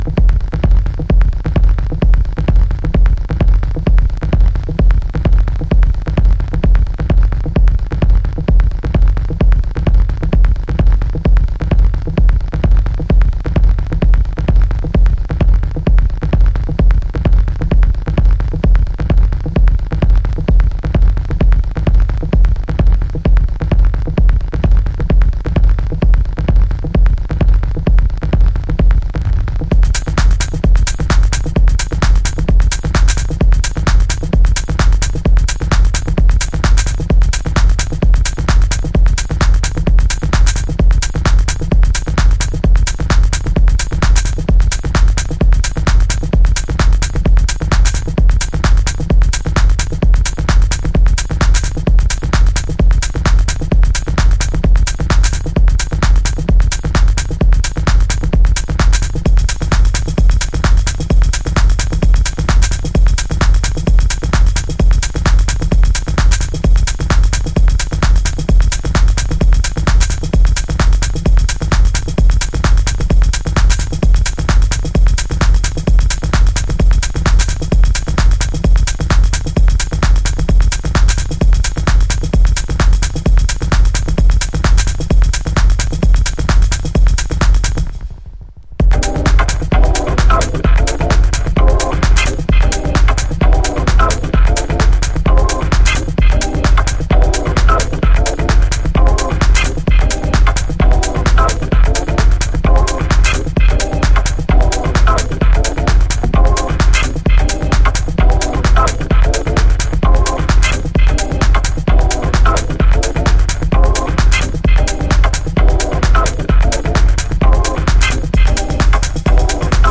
House / Techno
プリミティブなマシーンテクノ。